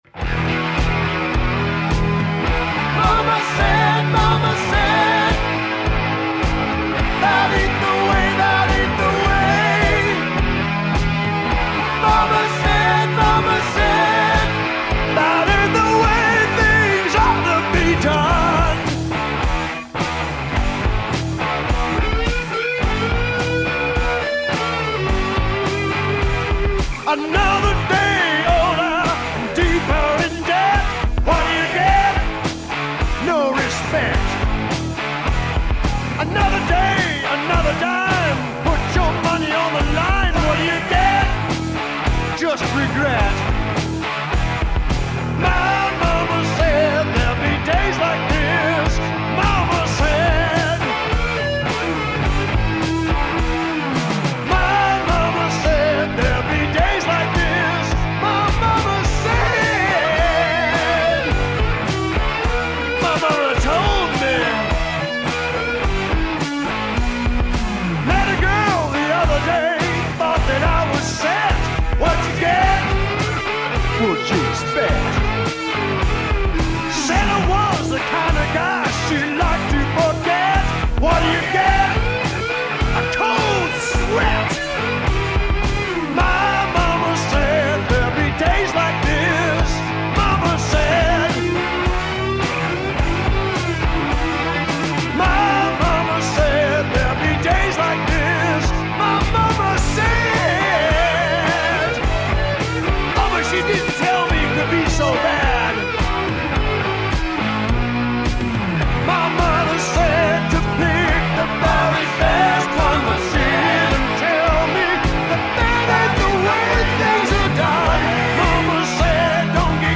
I like the solo guitar which has that great American Woman sound.